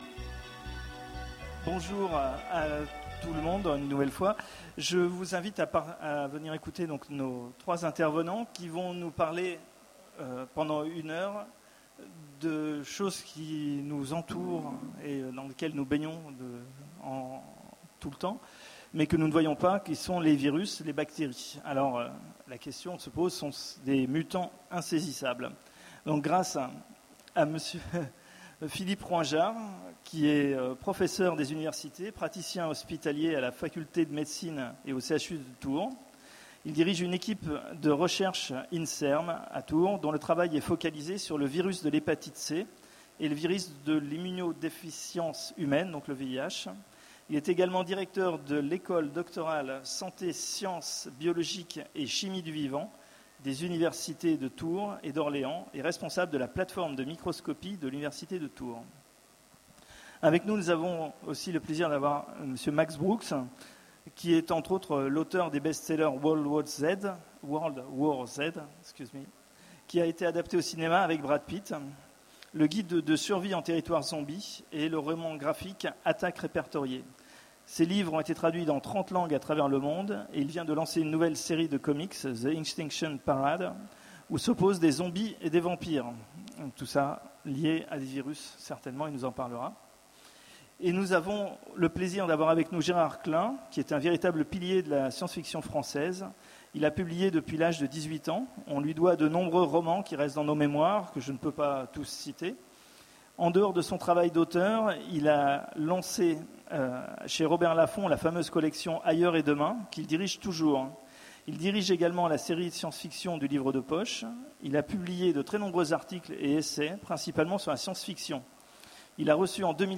Utopiales 13 : Conférence Virus, bactéries, des mutants insaisissables ?
(Malheureusement, suite à un soucis technique, nous n'avons que le début de la conférence. Toutes nos excuses.)